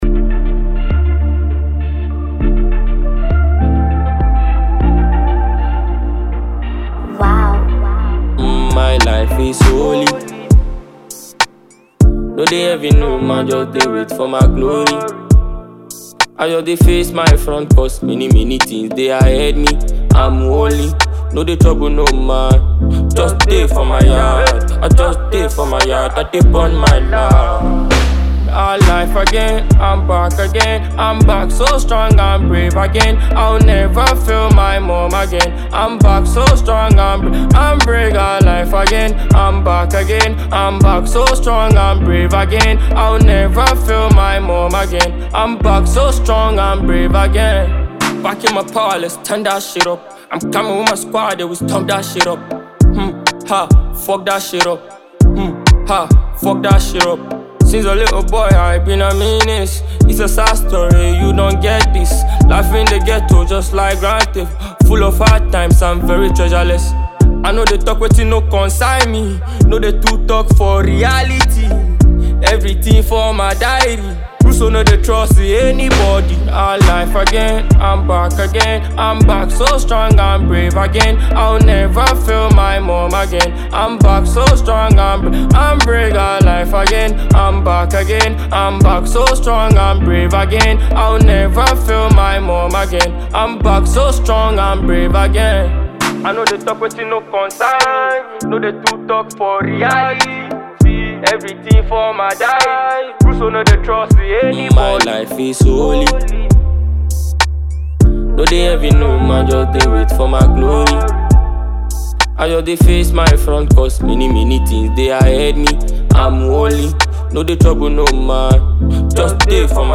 a talented Ghanaian dancehall artist